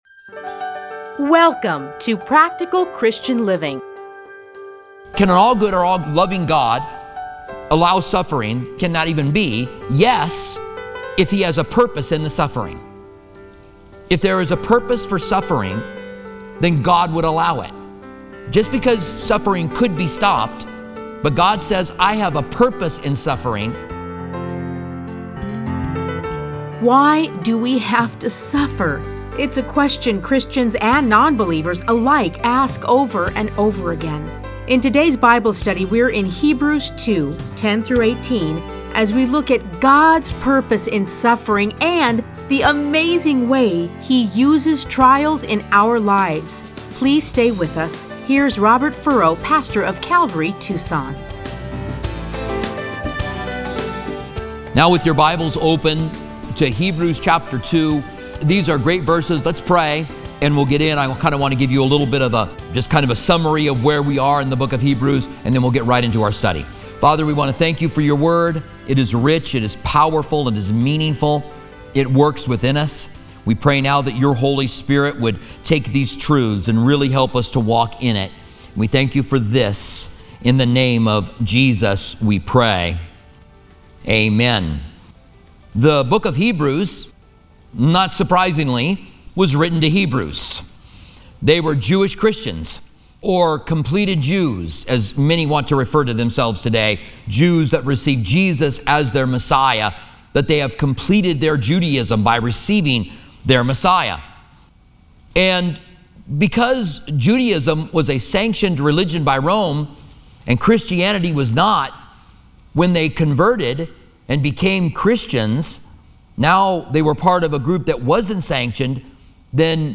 Listen to a teaching from Hebrews 2:10-18.